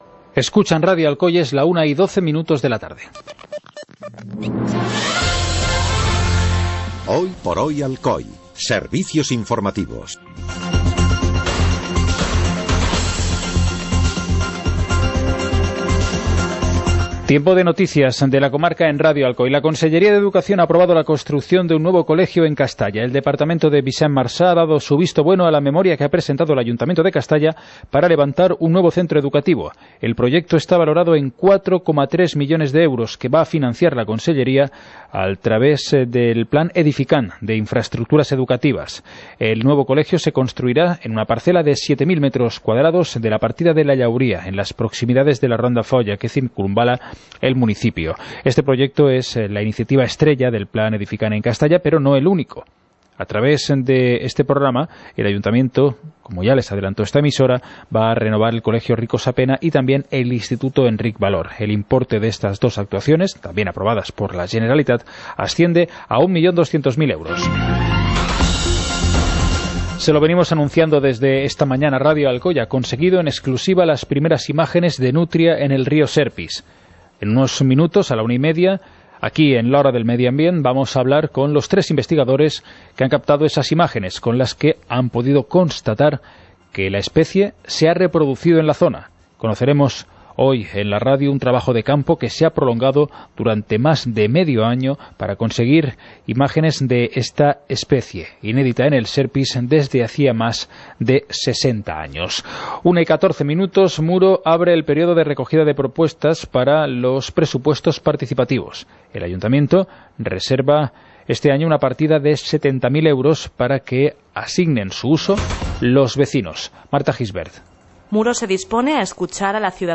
Informativo comarcal - viernes, 22 de junio de 2018